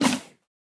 Minecraft Version Minecraft Version snapshot Latest Release | Latest Snapshot snapshot / assets / minecraft / sounds / mob / mooshroom / eat1.ogg Compare With Compare With Latest Release | Latest Snapshot
eat1.ogg